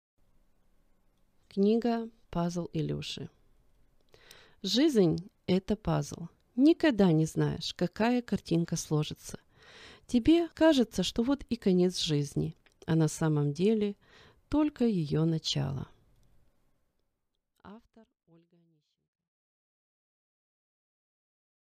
Аудиокнига Пазл Илюши | Библиотека аудиокниг